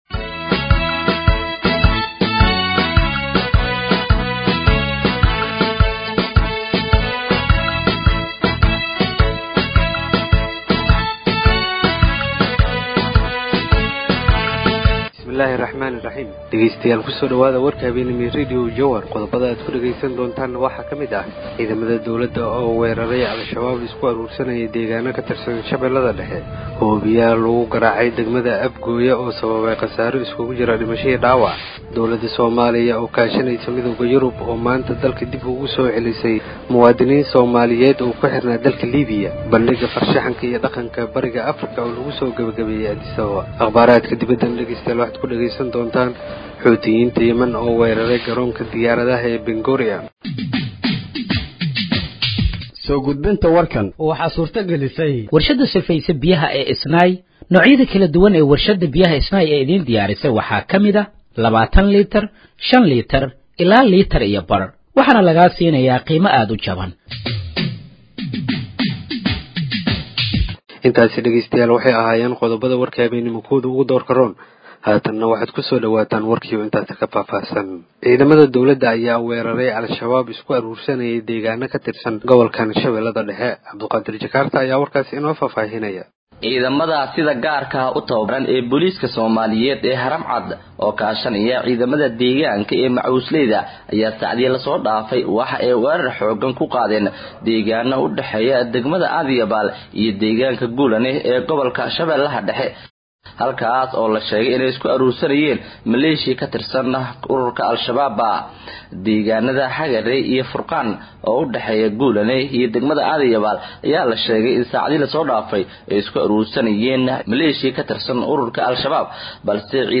Dhageeyso Warka Habeenimo ee Radiojowhar 24/03/2025